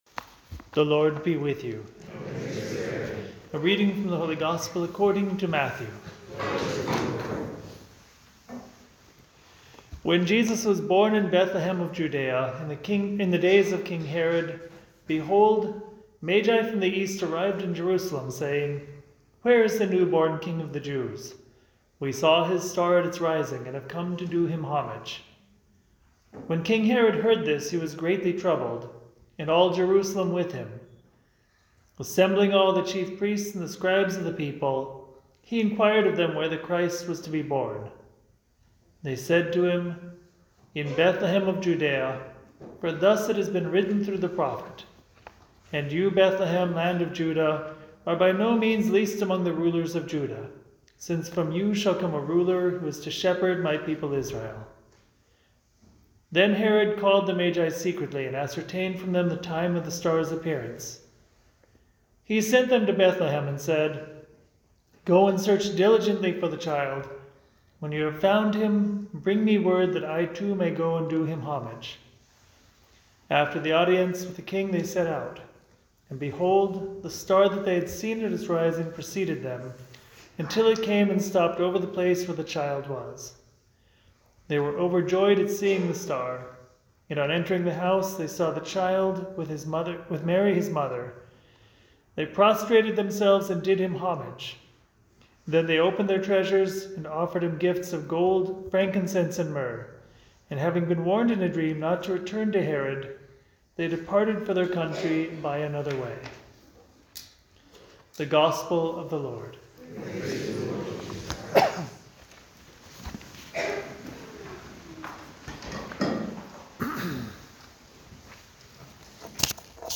Homily
on the Epiphany of the Lord at St. Patrick Church in Armonk, NY.